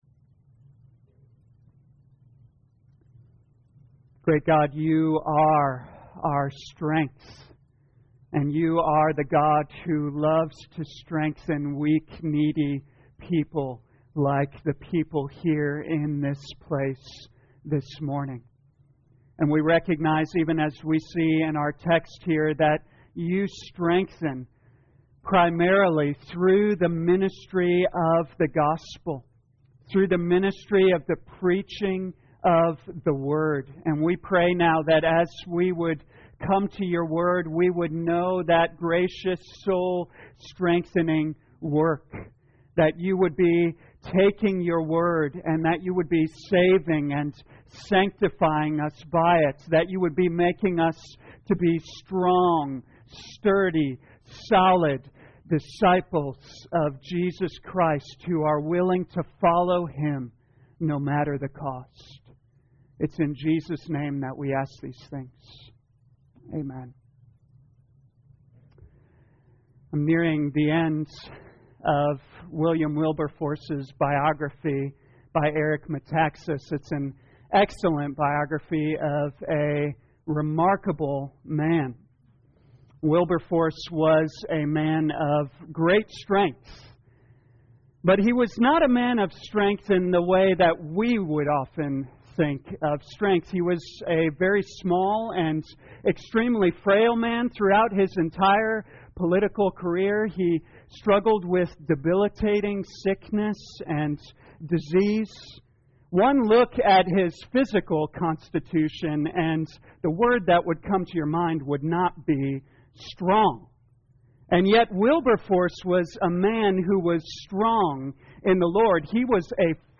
2022 Acts Morning Service Download
You are free to download this sermon for personal use or share this page to Social Media. A Mission of Strength Scripture: Acts 15:36-41